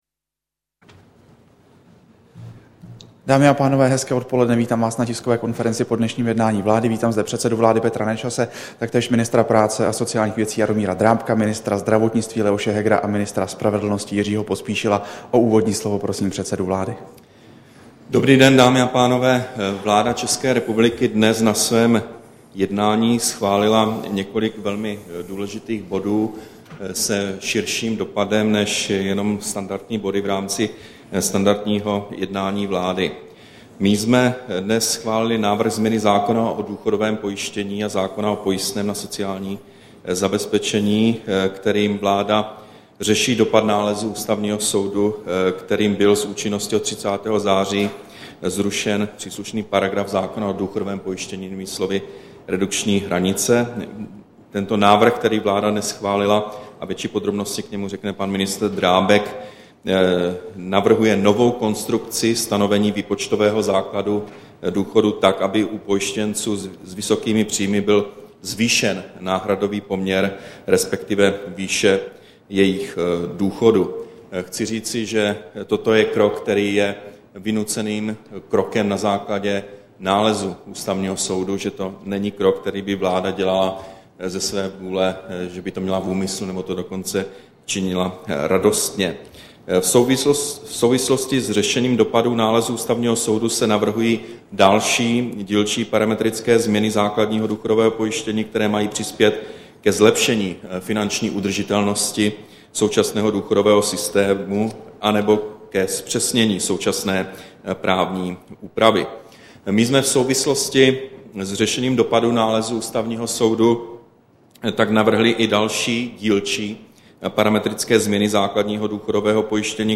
Tisková konference po jednání vlády, 23. února 2011